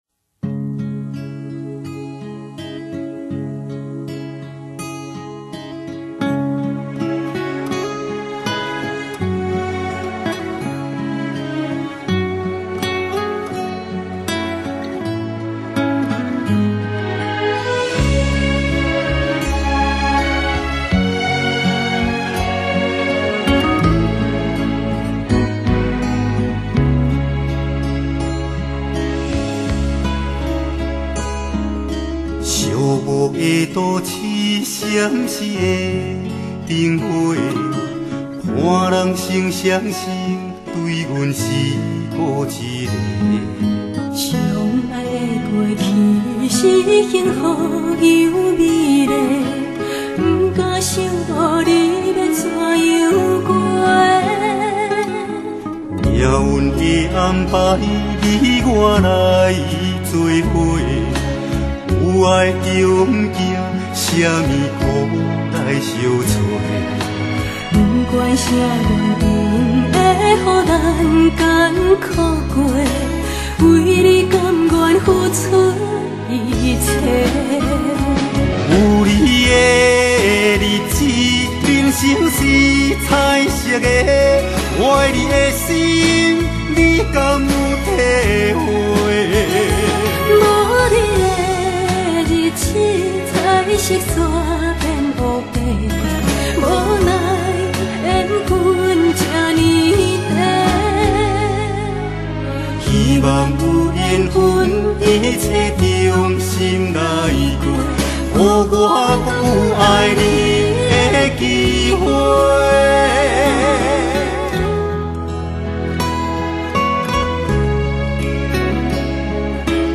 男女合唱的歌曲